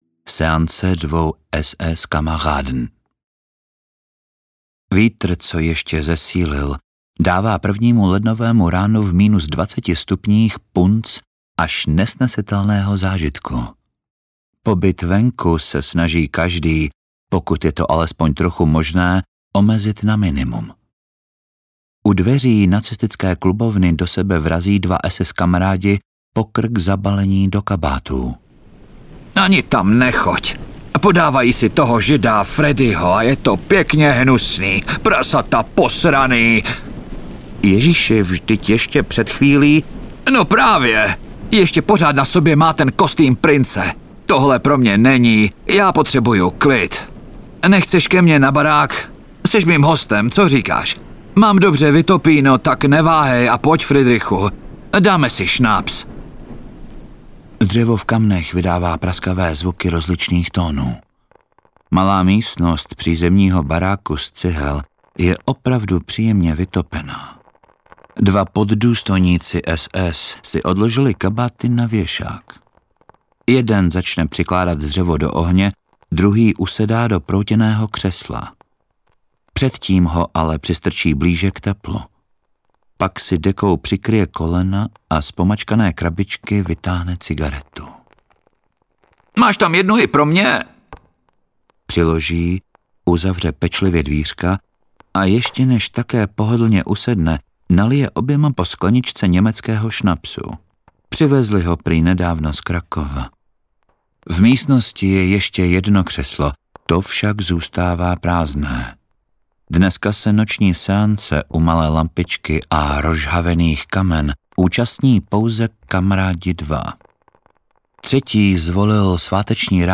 Jom Kipur audiokniha
Ukázka z knihy